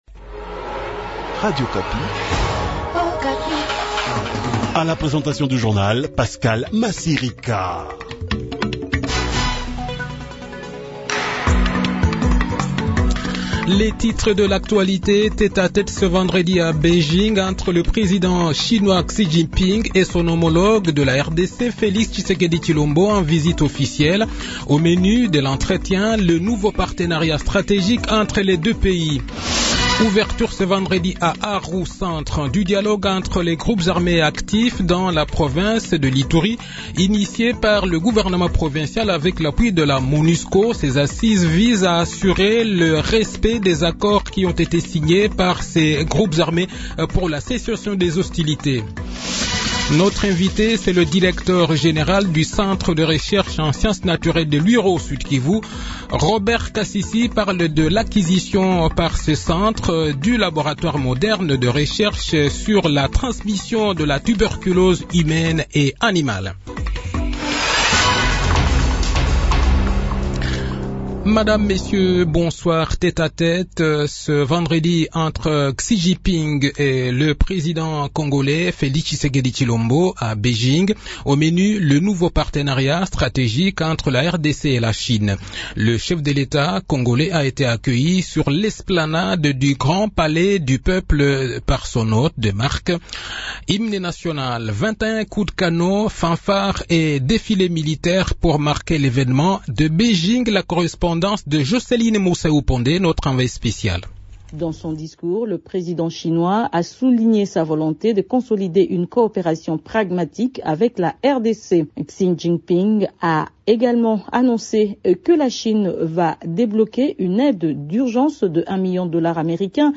Le journal de 18 h,n 26 Mai 2023